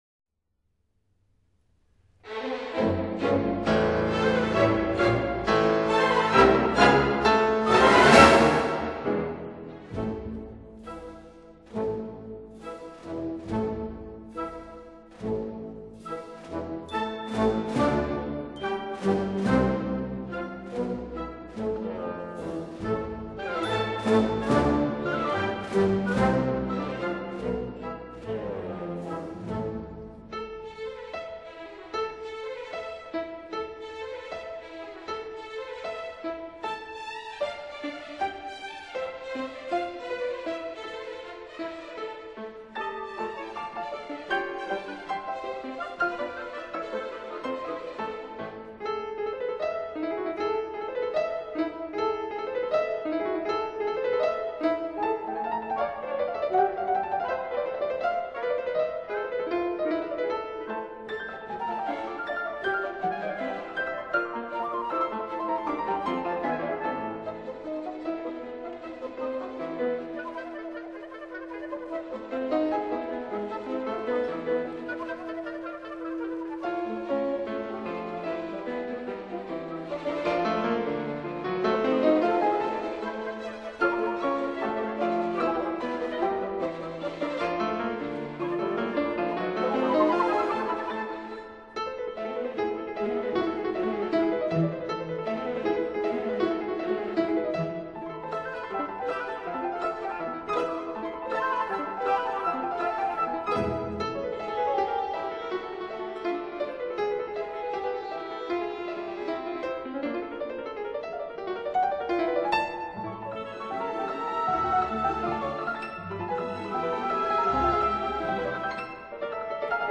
for Piano and Orchestra
piano